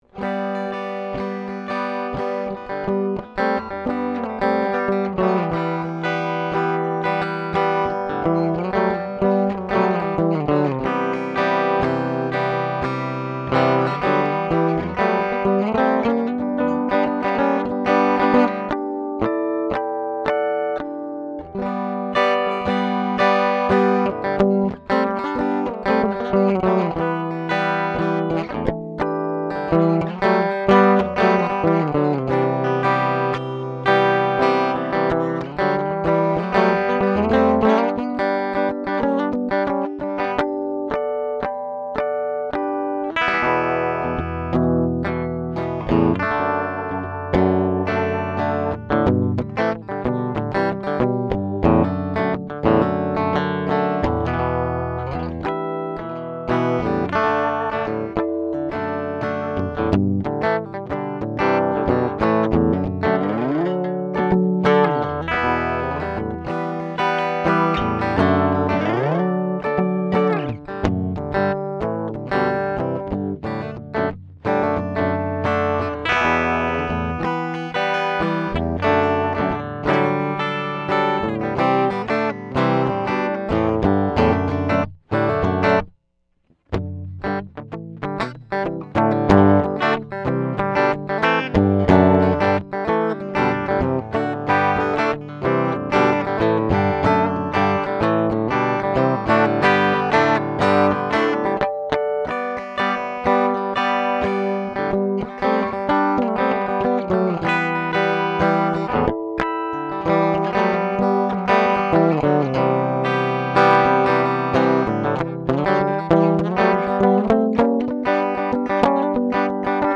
The upgraded Seymour Duncan P90 pickups smooth out the tone, and give it more definition and focus. The neck pickup still gives you plenty of bass, and gives your sound a warmth and glow that seems just right.
The Les Paul Studio / Vintage Mahogany / Worn Brown / Phat Cat SPH90 is played here to give you an idea of what to expect. The signal chain is direct DI via an Undertone Audio MPEQ-1, to a Metric Halo ULN-8 converter. The distortion sounds are from a Doc Scary / Scary Drive unit. No EQ, processiong, or any other effects were used: